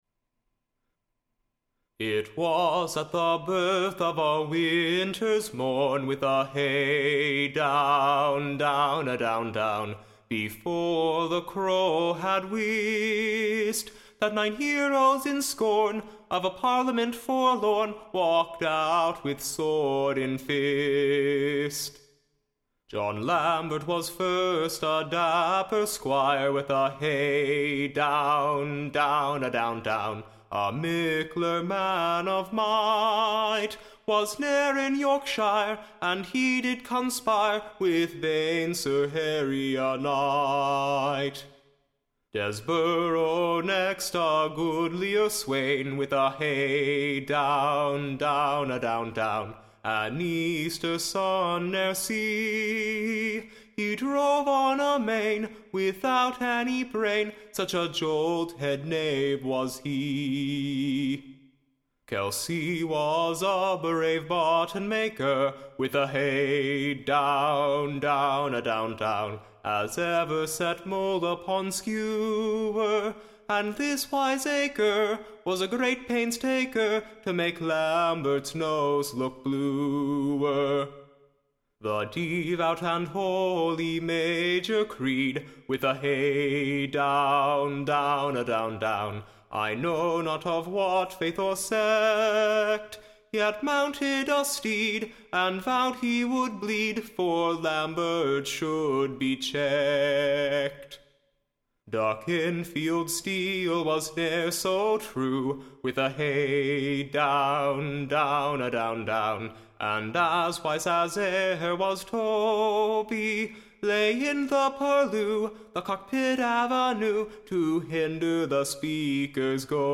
Ballad